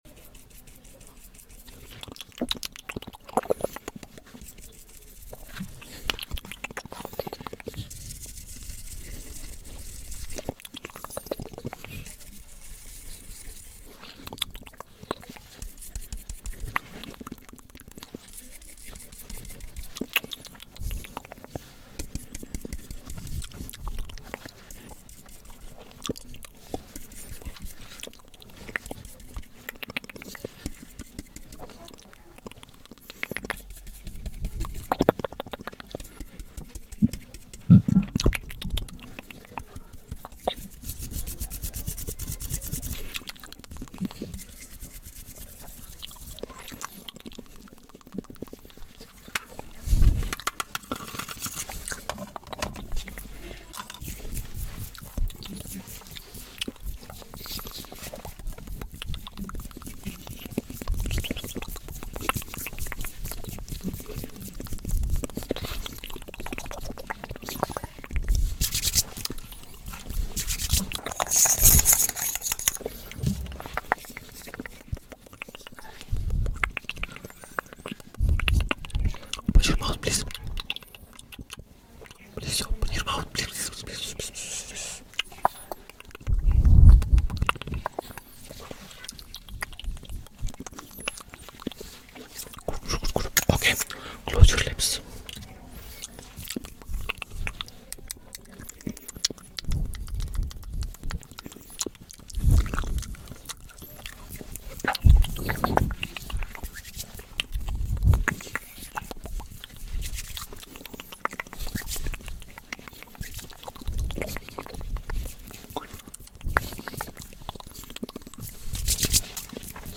ASMR indiano te maquiando canal: sound effects free download